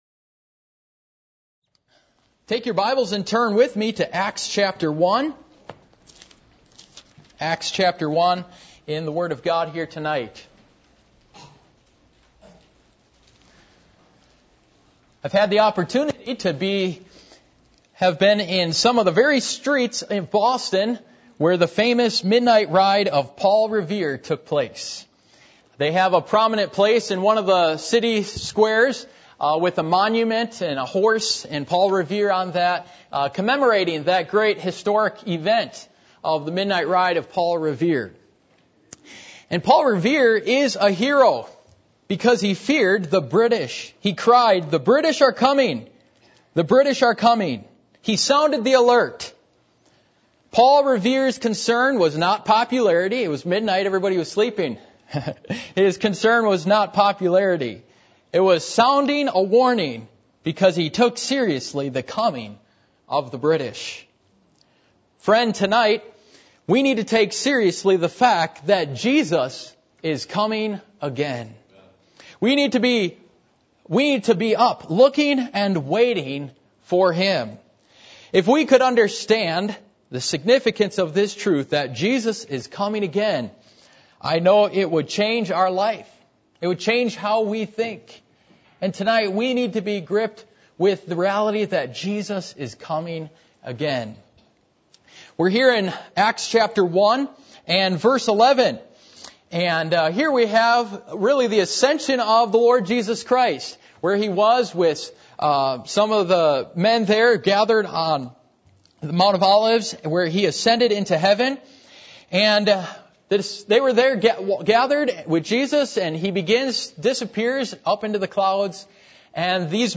Passage: Acts 1:11, 1 Thessalonians 4:13-18 Service Type: Sunday Evening